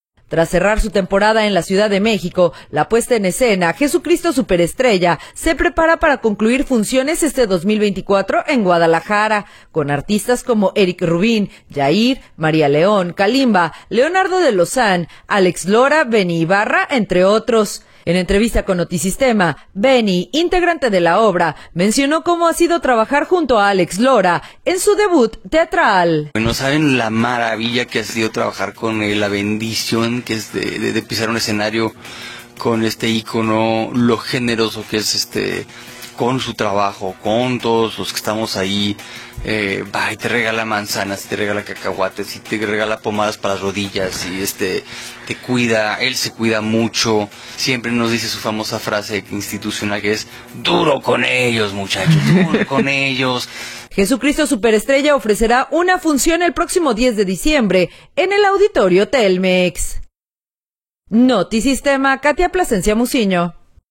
En entrevista con Notisitema, Benny Ibarra, integrante de la obra, mencionó como ha sido trabajar junto a Alex Lora en su debut teatral.